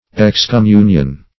Search Result for " excommunion" : The Collaborative International Dictionary of English v.0.48: Excommunion \Ex`com*mun"ion\ A shutting out from communion; excommunication.